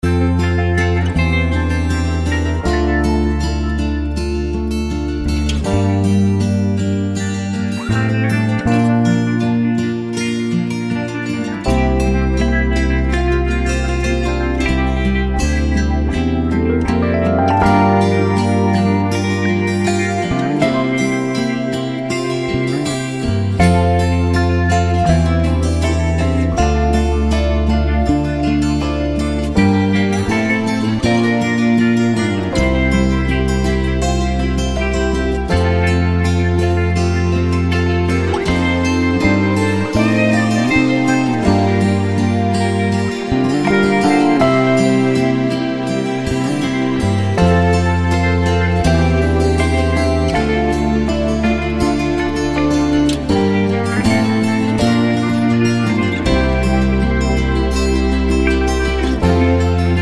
Pop (1970s)